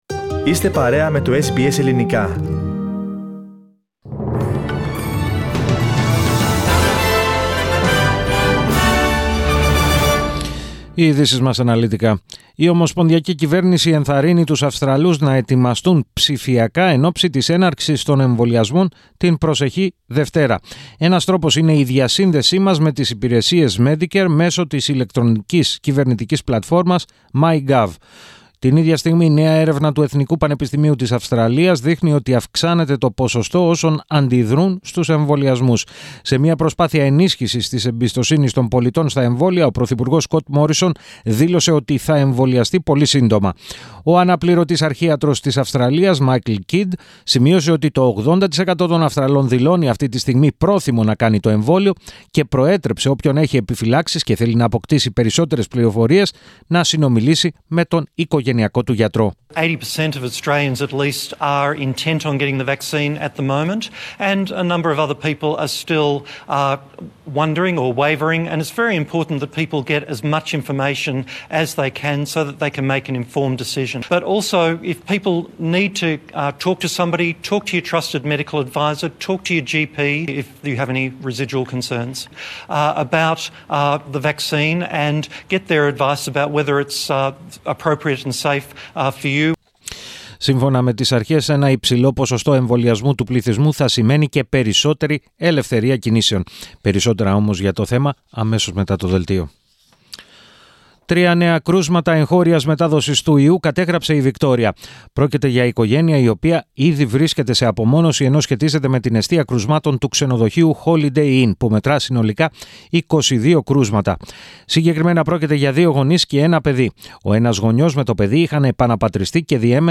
Ειδήσεις 19.02.21